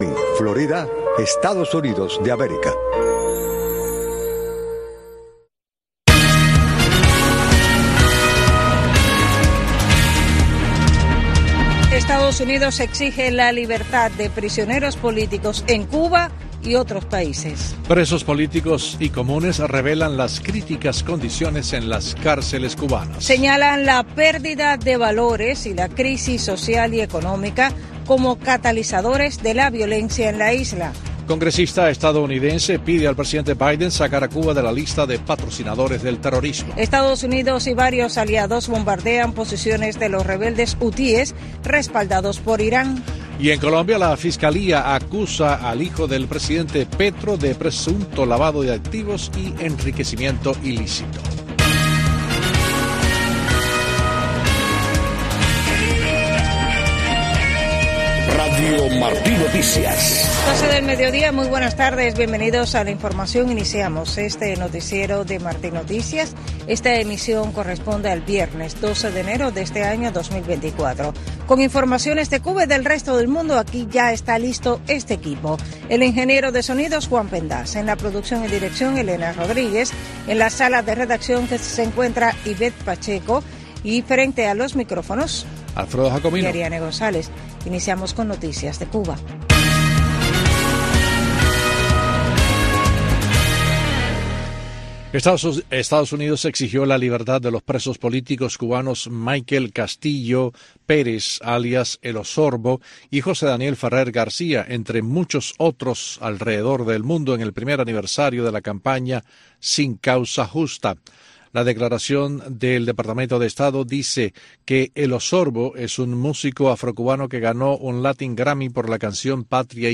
Noticiero de Radio Martí 12:00 PM | Primera media hora